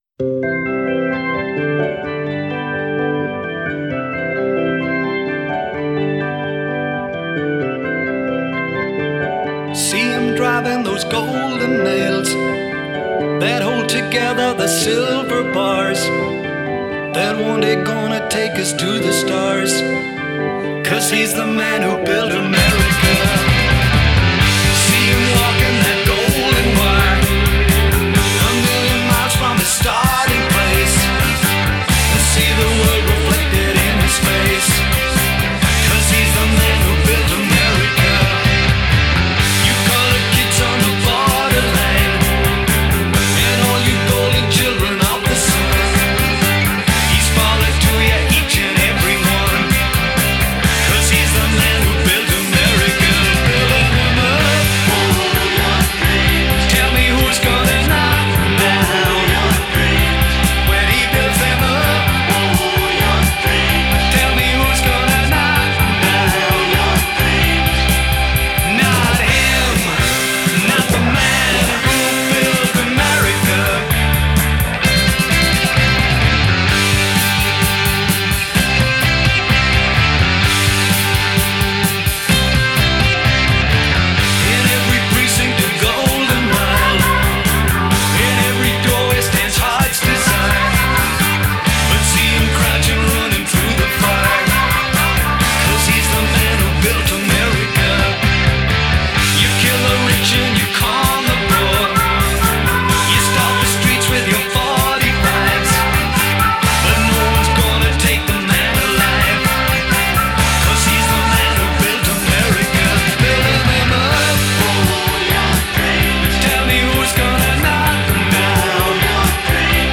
That is, until the flute solo.